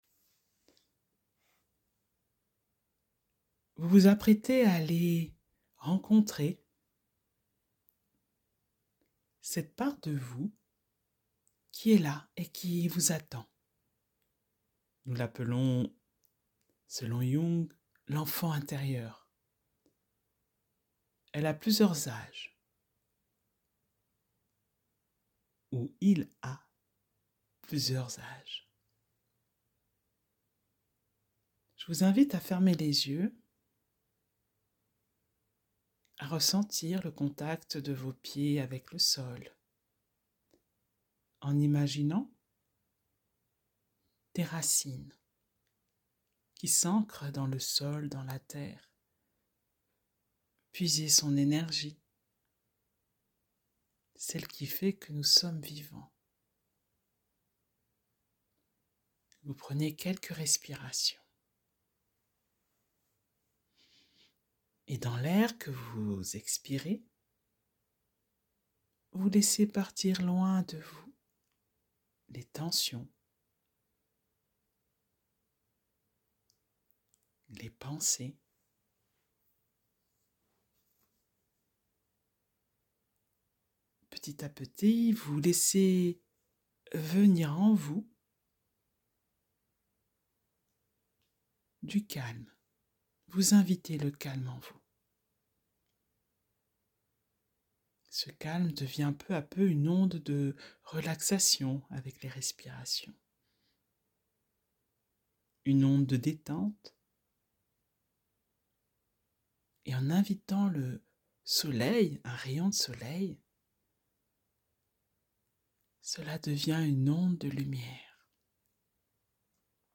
Une micro-séance de sophro-analyse* pour apprendre à aller à la rencontre de ce petit enfant en soi qui attend d’être écouté, regardé, rassuré. Une proposition pour commencer à dialoguer avec l’enfant en soi.